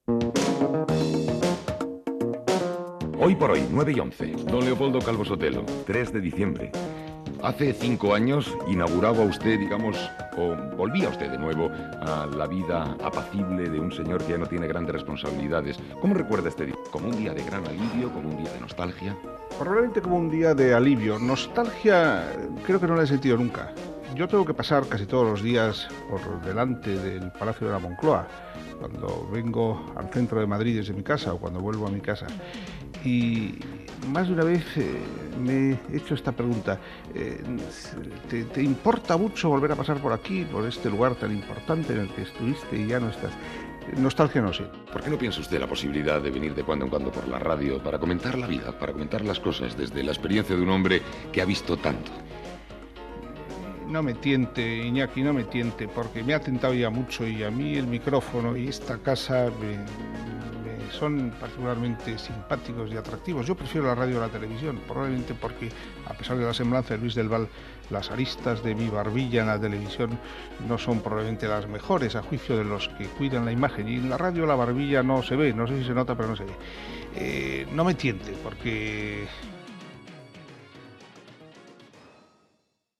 Hora, inici de l'entrevista al polític Leopolodo Calvo-Sotelo, cinc anys després que deixés de ser president del Govern espanyol
Info-entreteniment